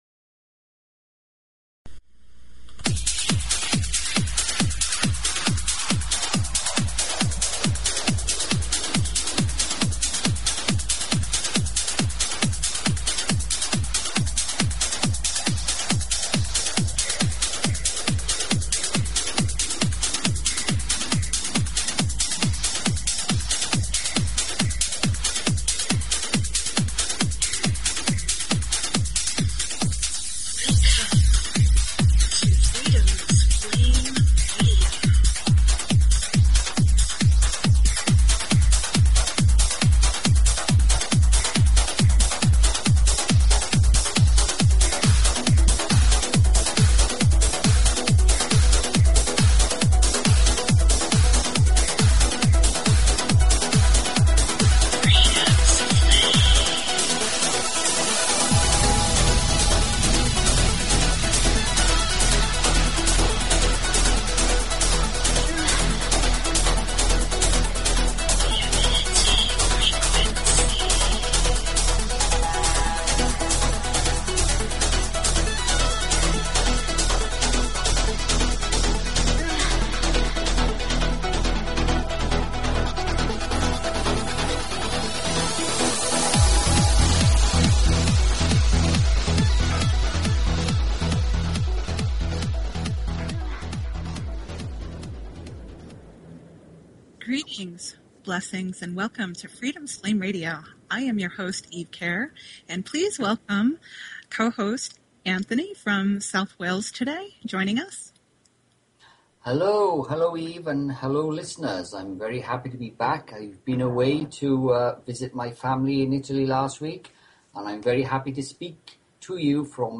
Talk Show Episode, Audio Podcast, Freedoms_Flame_Radio and Courtesy of BBS Radio on , show guests , about , categorized as